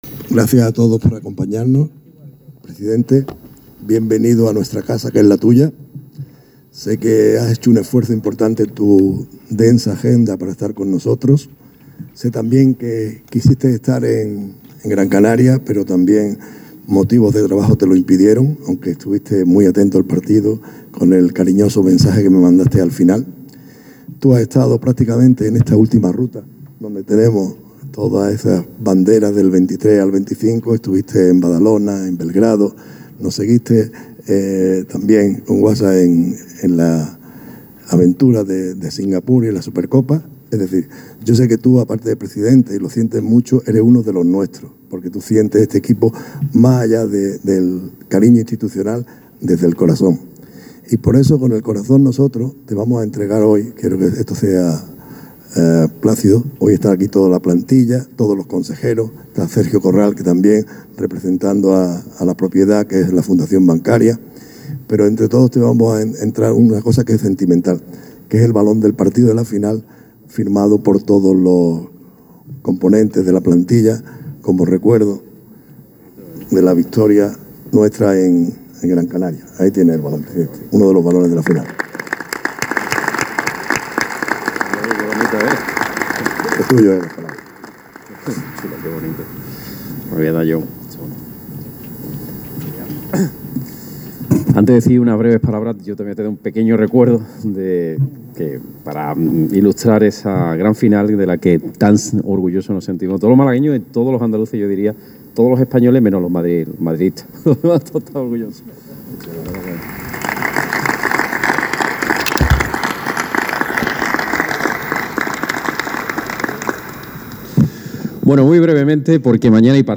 Jugadores y staff, al completo, esperaban en el centro de la cancha del Martín Carpena.
Siempre presente el luchado trofeo copero, situado junto al micrófono que iba a dar voz al acto.
De forma seguida, era Juanma Moreno quien tomaba el relevo del micrófono antes de la finalización del acto.